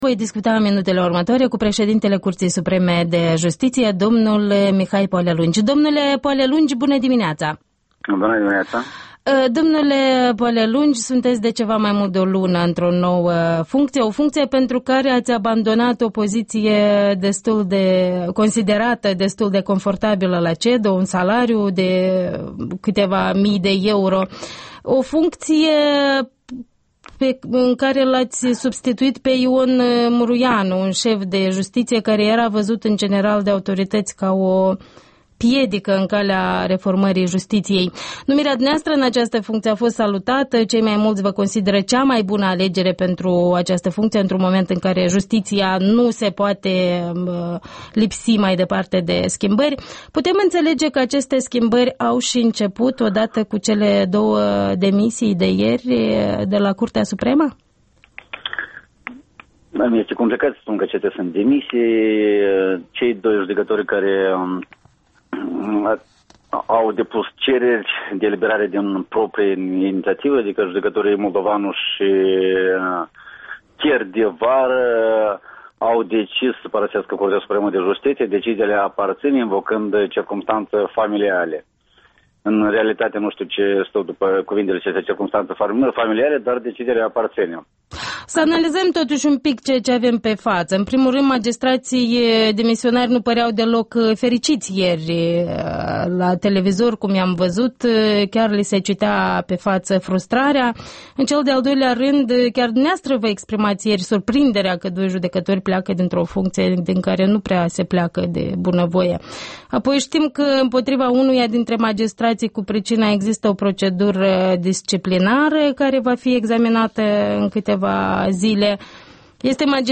Interviul dimineții la EL: cu Mihai Poalelungi, președintele Curții Supreme de Justiție